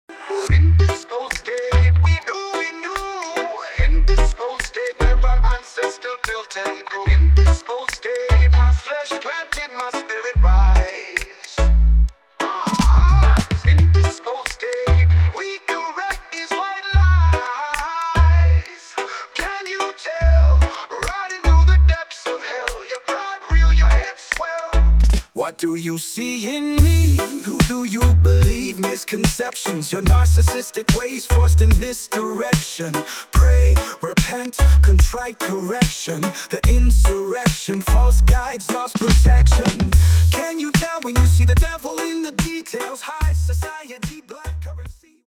An incredible Reggae song, creative and inspiring.